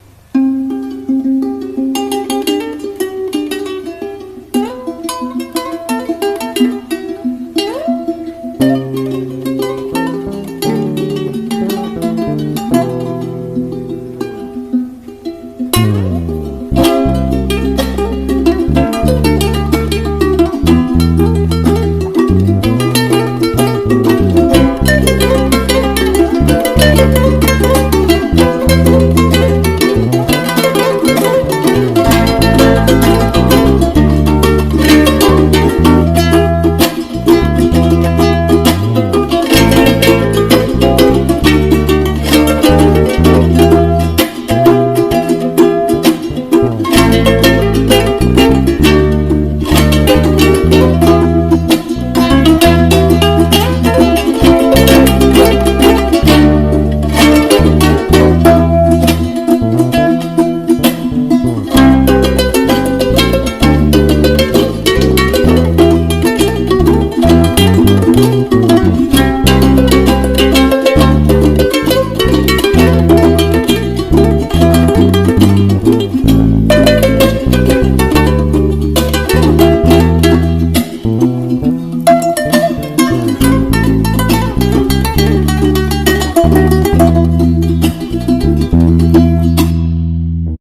Грузинская песня